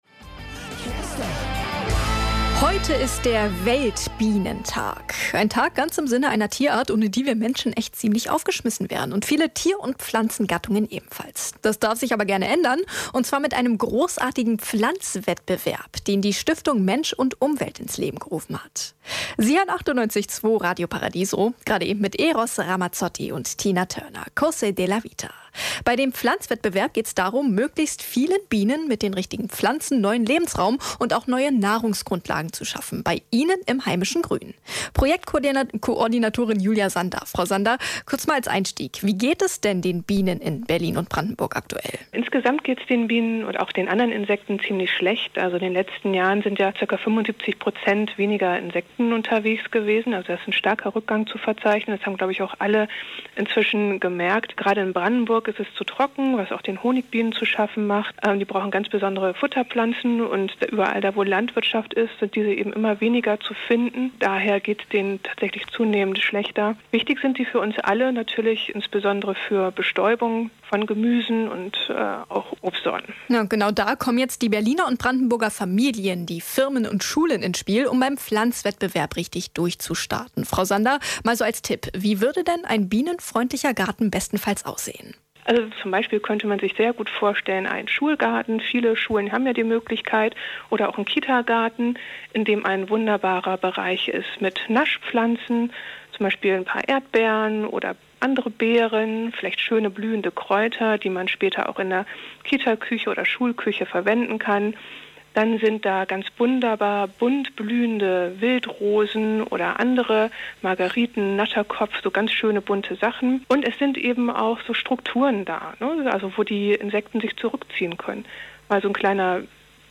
Radio-Mitschnitt!